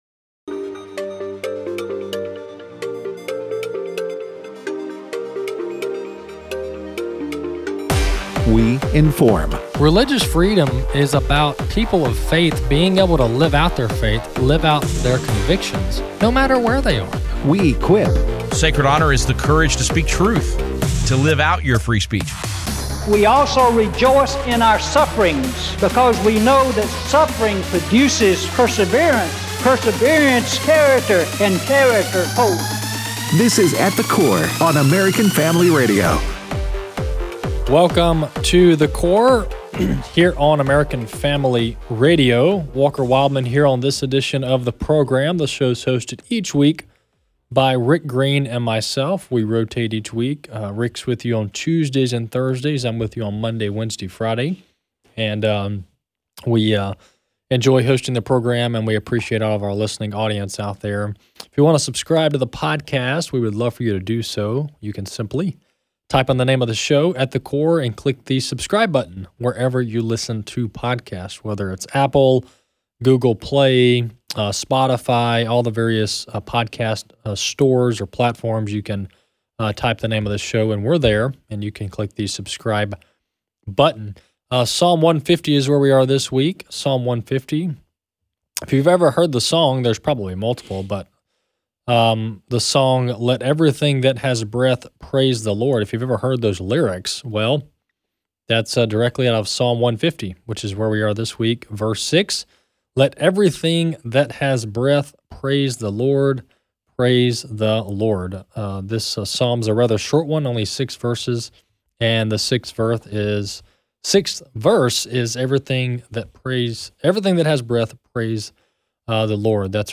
in studio, to discuss the Foundation, retirement, and CGA’s 18:00